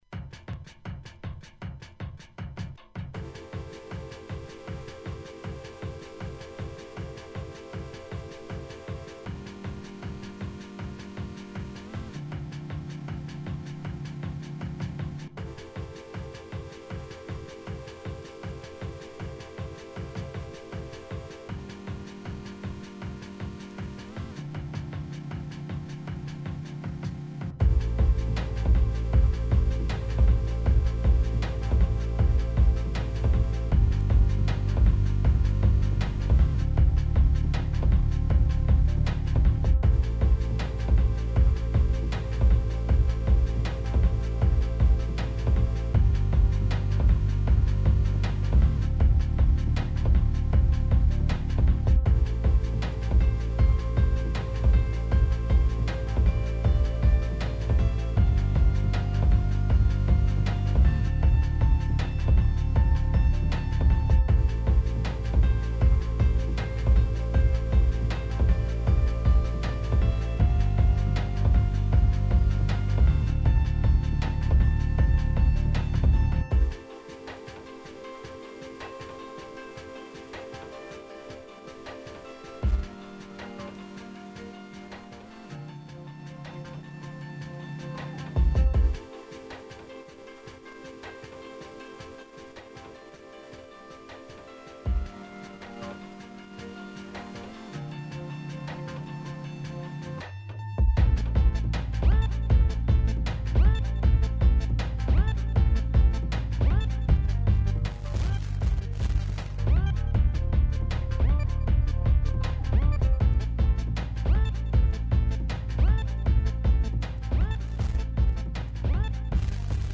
Euskrats: Acid Techno